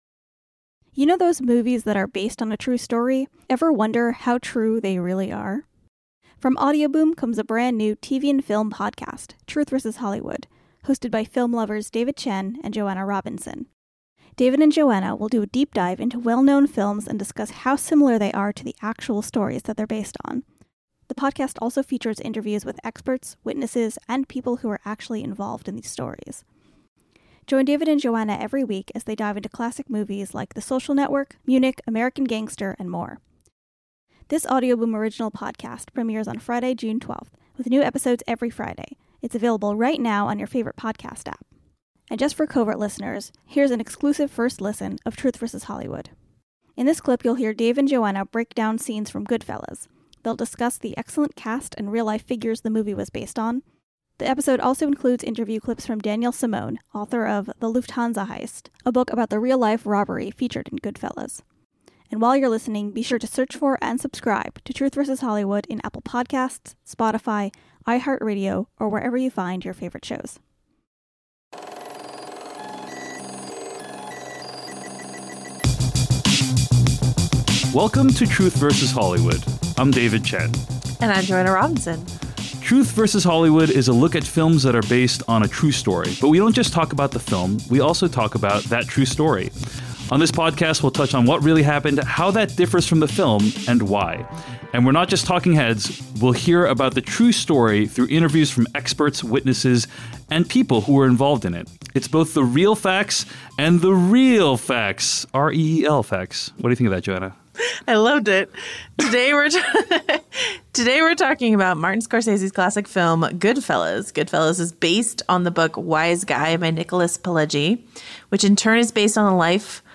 The discussion also features interviews with experts, historians, and people who were involved in the real stories.